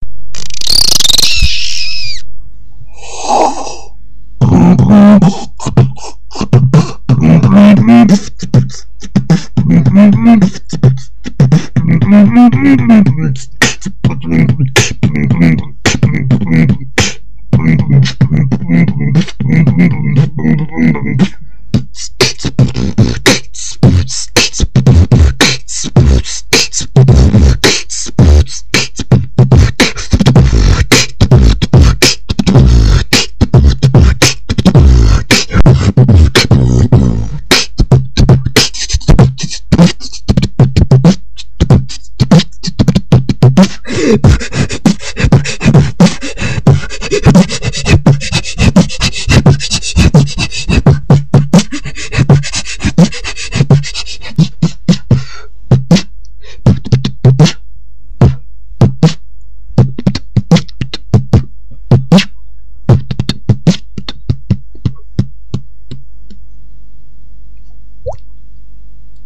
Фристайл
Честно признаюсь, немного обработал, лишнее вырезал и громкости добавил, вообщем слушайте))
включаю я значит вторую запись, я уже не говорю что второй звук уже убил, попытки сделать насал просто повергли в страх, ибо, он очевидно получается хреново, и ты решил, что чем громче и сильнее - тем лучше....